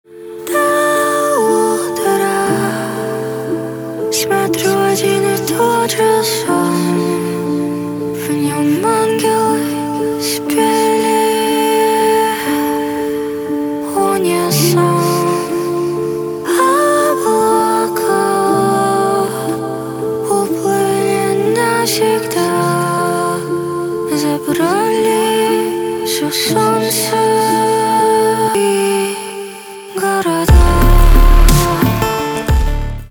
поп
грустные , печальные , пианино , спокойные , битовые , басы